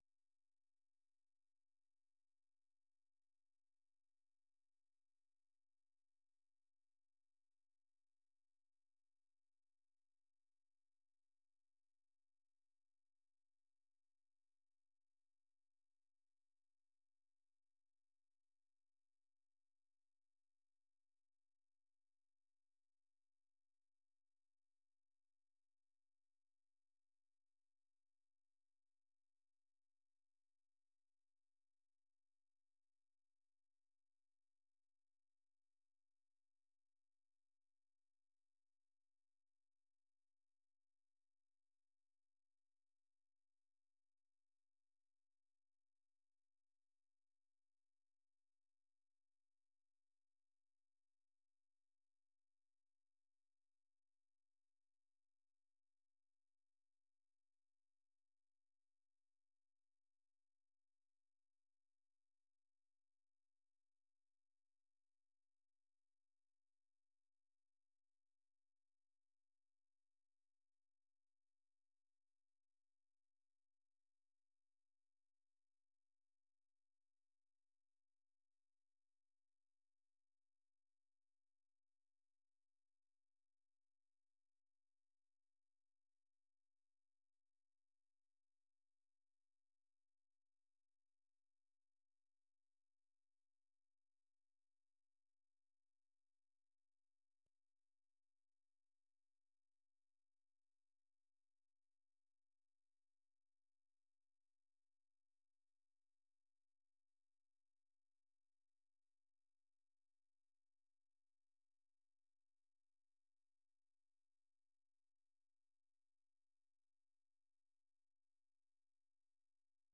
ማክሰኞ፡- ከምሽቱ ሦስት ሰዓት የአማርኛ ዜና